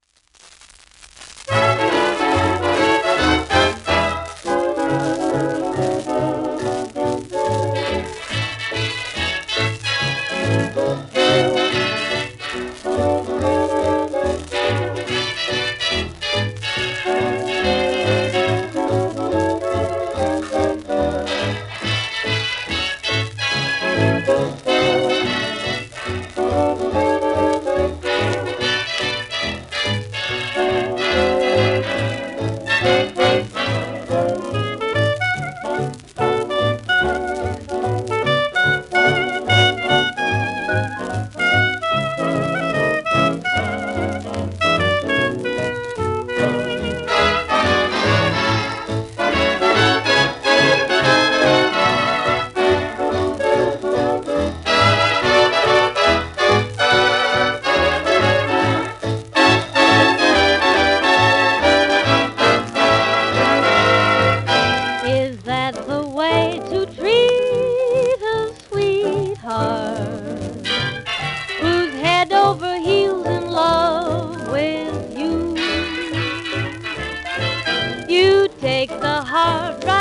盤質B+ *サーフェイスノイズ